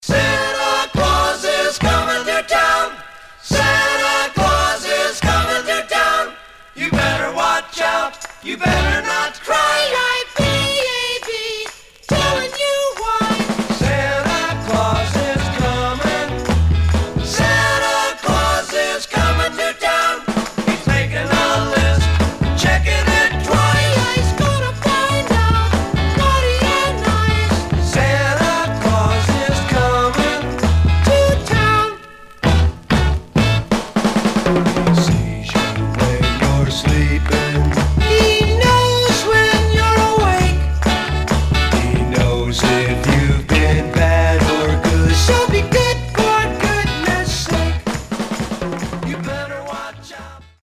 Like 4 Seasons...Flip Side Novelty Condition: VG+/M- WPC
Some surface noise/wear
Mono